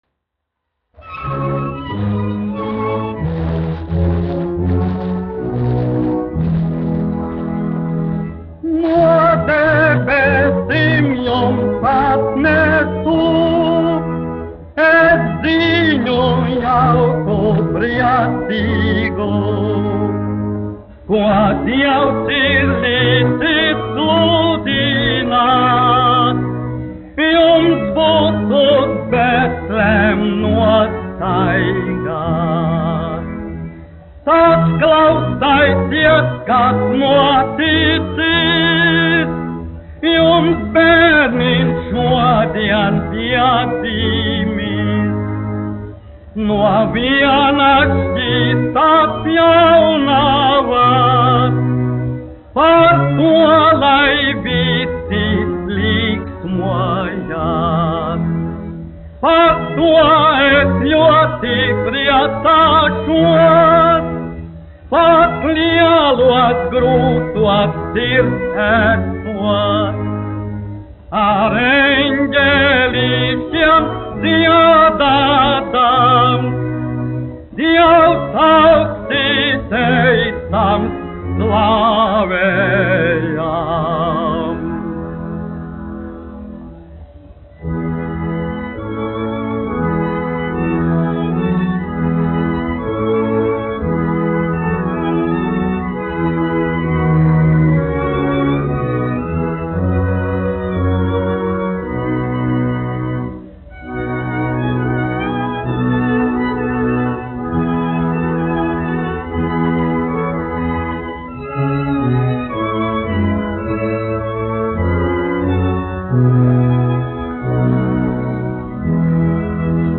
1 skpl. : analogs, 78 apgr/min, mono ; 25 cm
Ziemassvētku mūzika
Skaņuplate